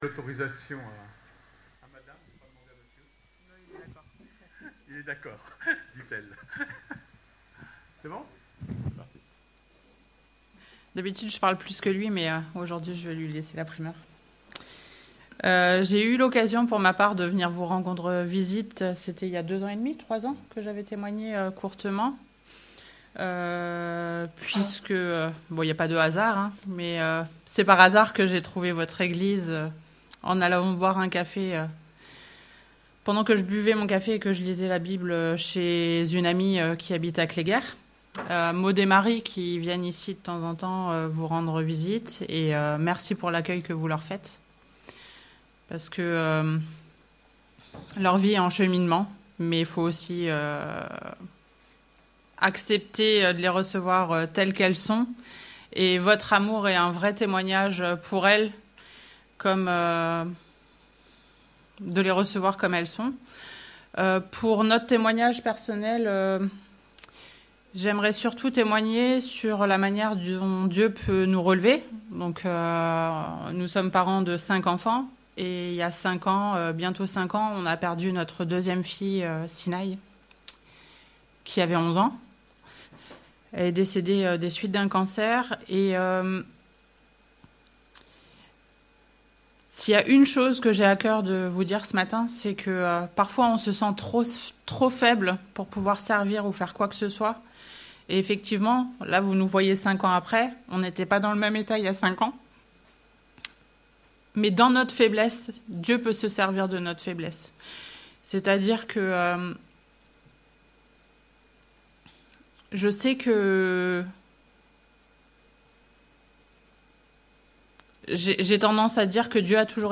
Prédication : Les tentations, fondement de nos sociétés ?